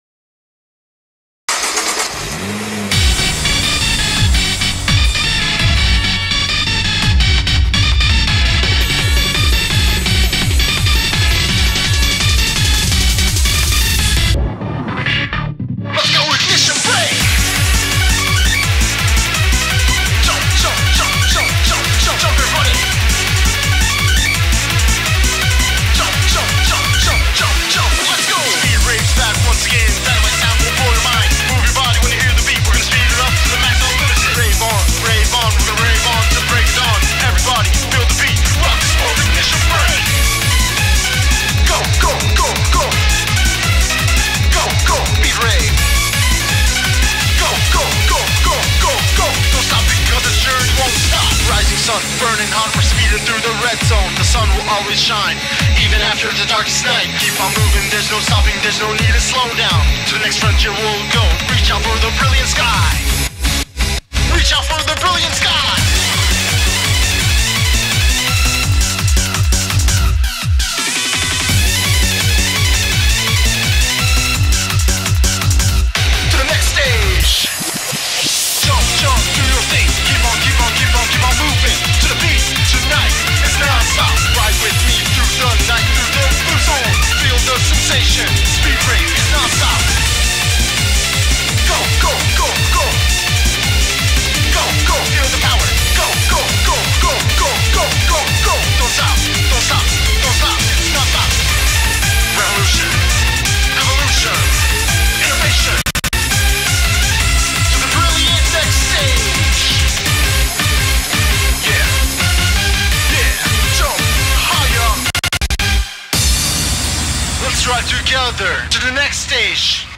BPM168
Audio QualityCut From Video